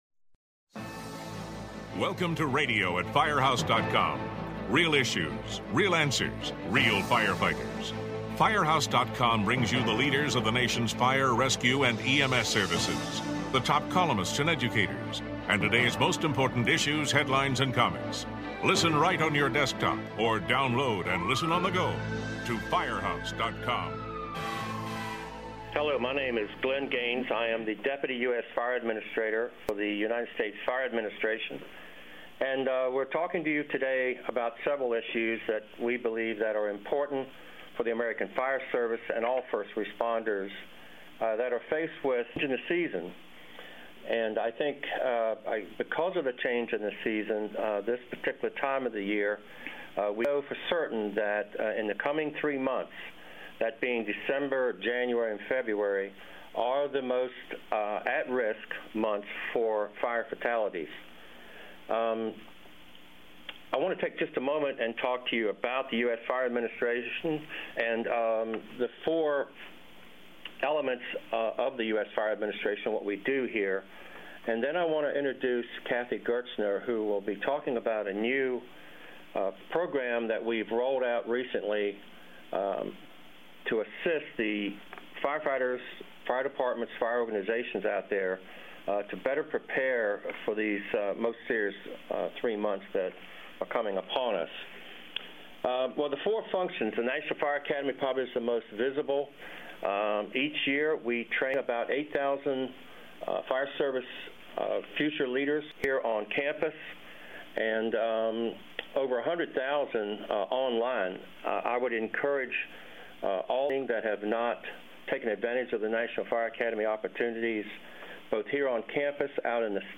U.S. Fire Administration Deputy Administrator Chief Glenn Gaines hosts a podcast that looks at a variety of programs being offered by the U.S. Fire Administration and those taking place at the National Fire Academy (NFA) in Emmitsburg, MD.